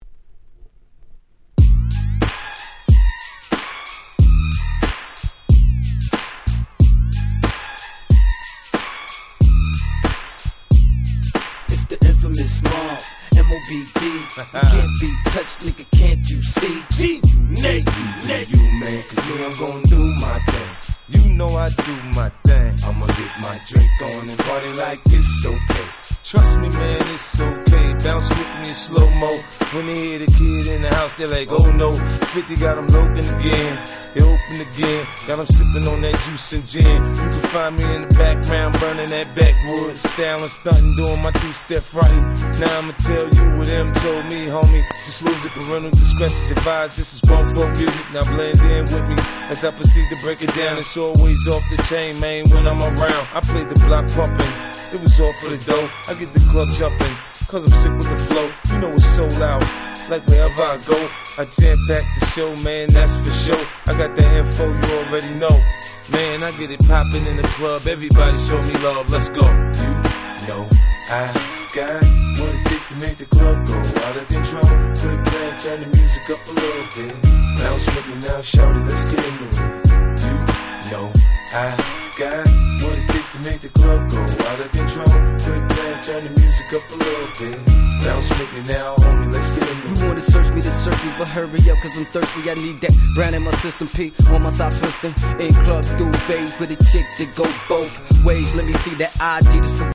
05' Smash Hit Hip Hop !!